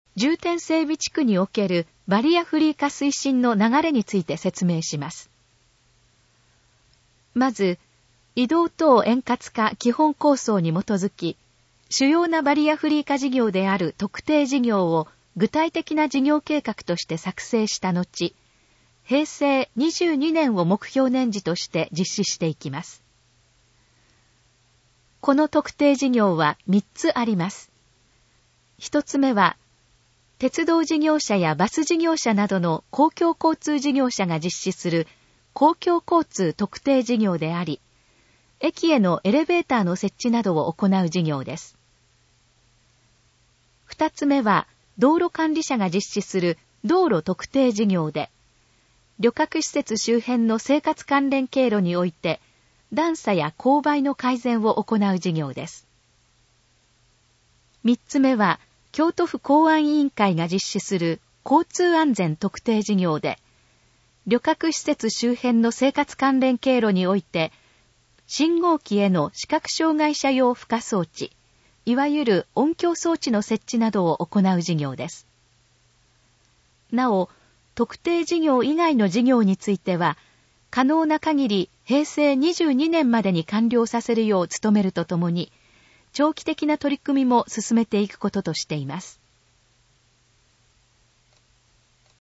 このページの要約を音声で読み上げます。
ナレーション再生 約361KB